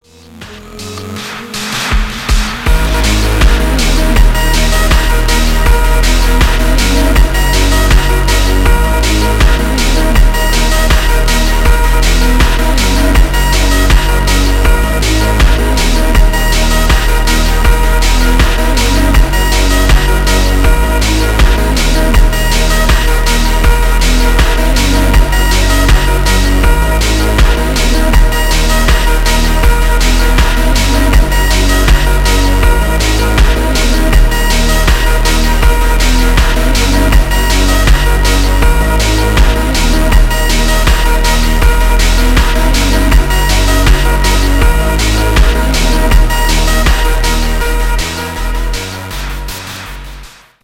remix
без слов
красивая мелодия
медленные
расслабляющие
phonk